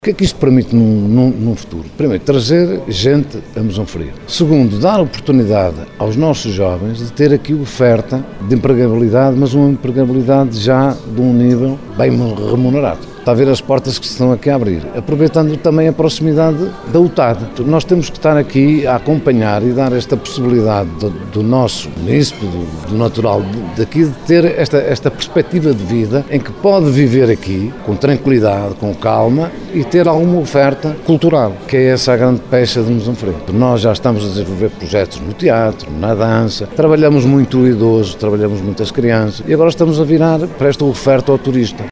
Paulo Silva presidente da câmara de Mesão Frio, diz que o projeto pretende captar jovens para o concelho: